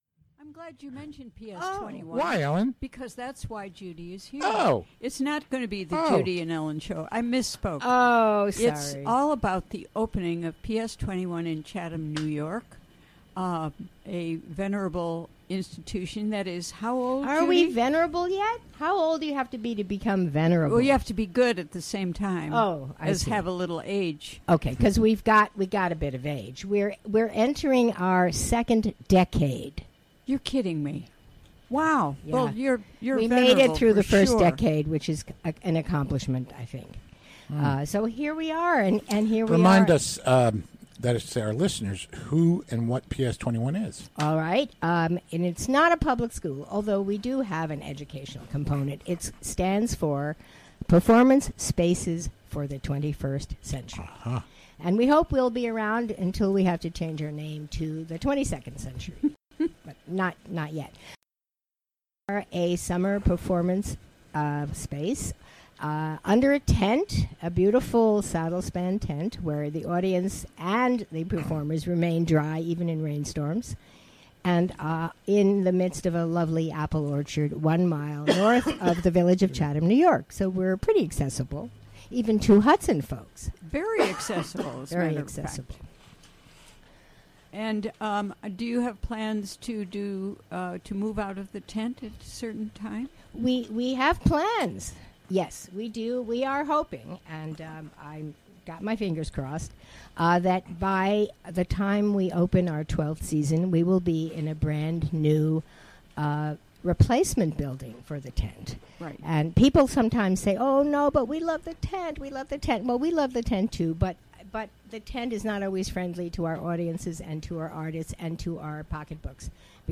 Interview from the WGXC Afternoon Show on Thursday, May 12.